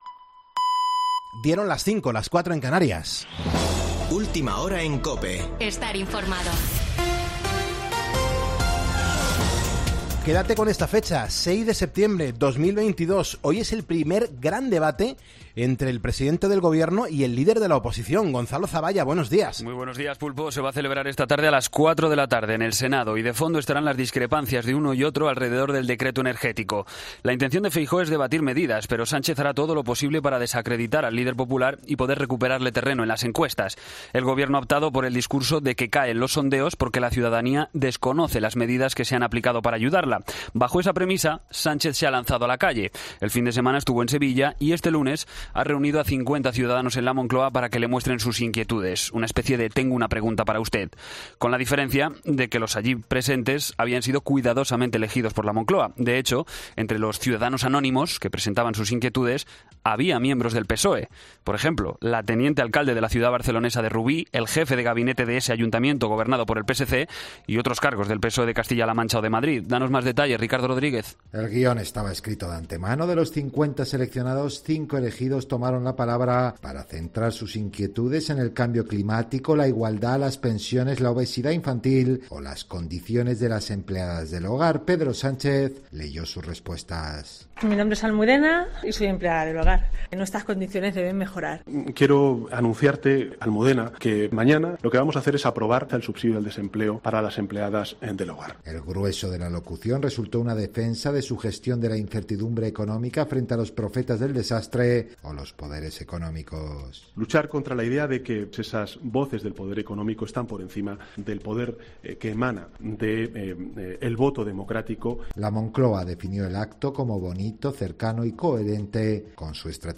Boletín de noticias COPE del 6 de septiembre a las 05:00 horas
AUDIO: Actualización de noticias Herrera en COPE